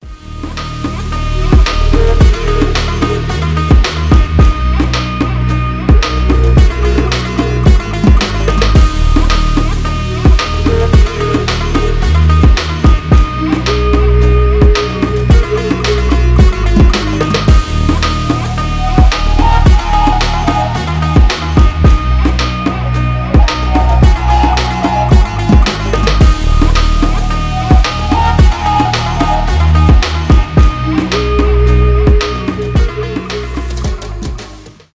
house , без слов , клубные , восточные